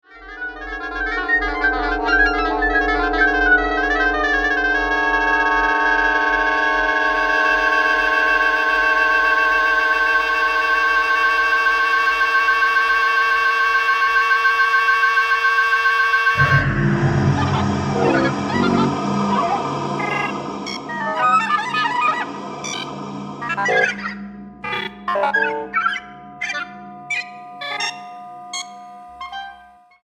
oboe y cinta